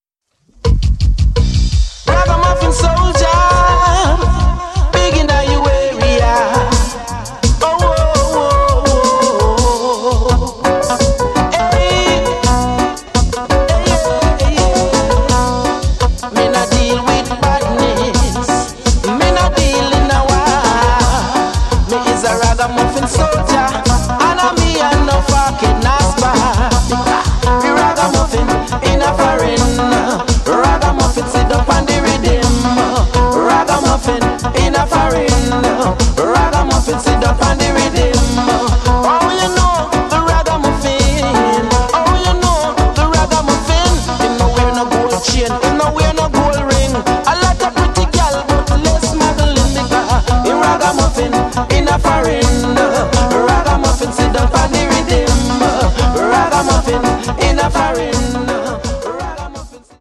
House Hip Hop Bass